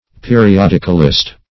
Search Result for " periodicalist" : The Collaborative International Dictionary of English v.0.48: Periodicalist \Pe`ri*od"ic*al*ist\, n. One who publishes, or writes for, a periodical.